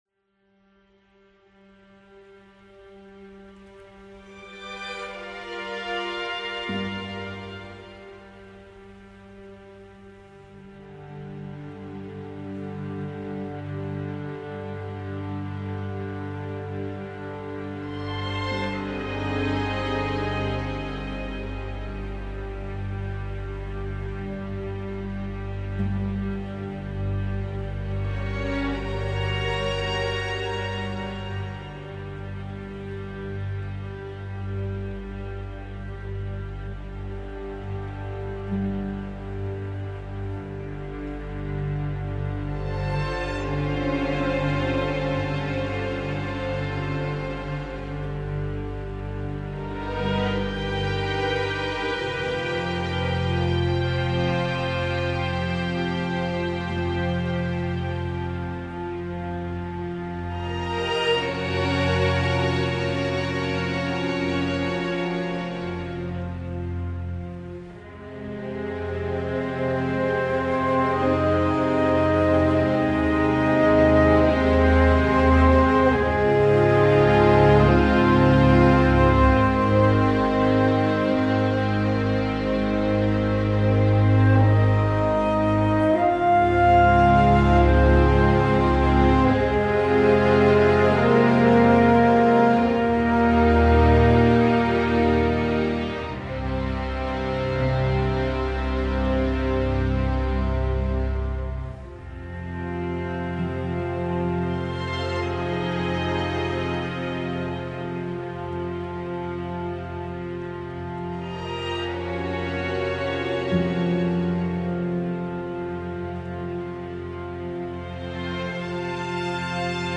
Soundtrack, Horror, Orchestral